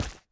resin_place1.ogg